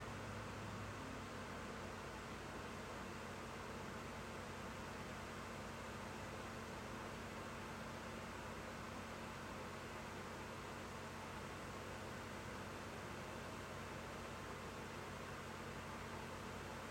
All clips were recorded under the same conditions, using an iPhone 16 Pro placed 3 feet away from the fan, with the fan running at full speed and blowing away from the microphone.